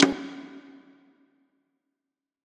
Pony Perc.wav